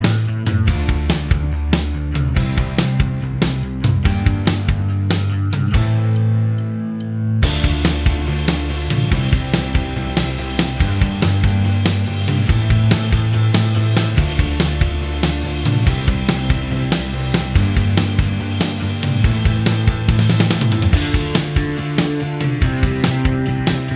Minus All Guitars Indie / Alternative 2:45 Buy £1.50